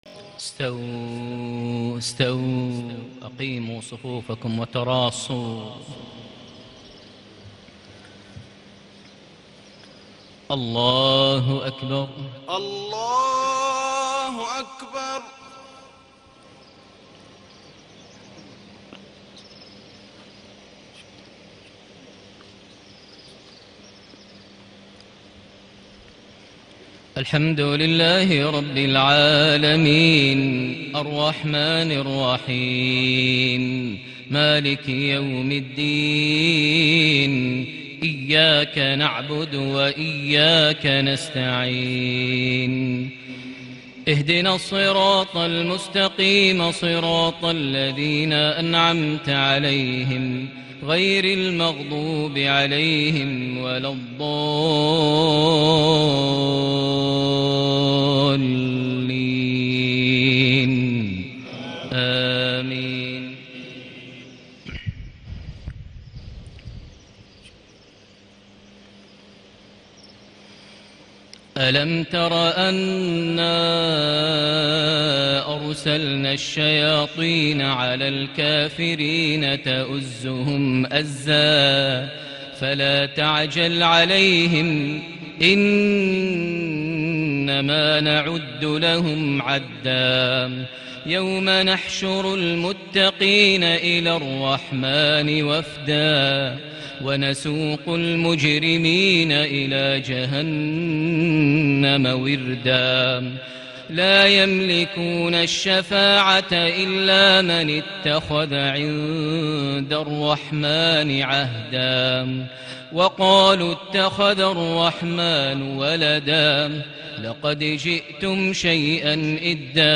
صلاة المغرب ٣ صفر ١٤٣٨هـ خواتيم سورة مريم > 1438 هـ > الفروض - تلاوات ماهر المعيقلي